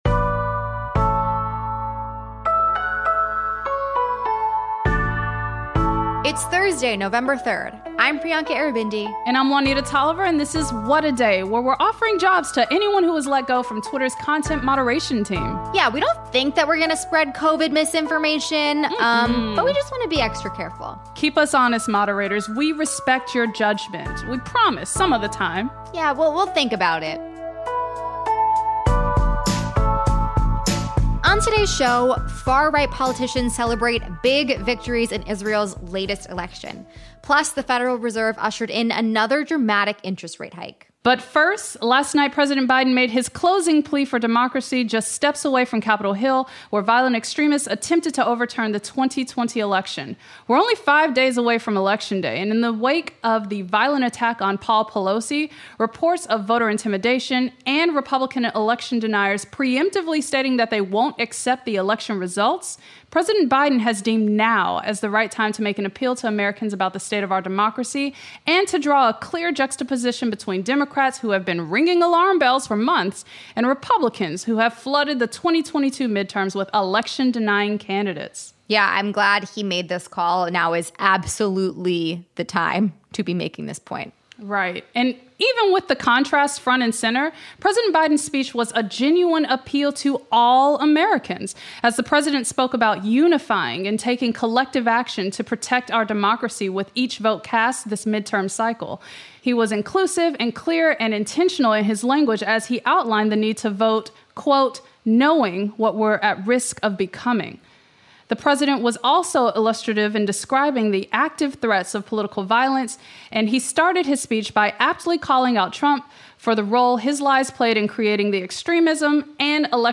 For our final installment of WAD The Vote, Rep. Sean Casten, the first Democrat to represent Illinois’ 6th Congressional District in decades, tells us how he entered politics, and why he’s running for a third term.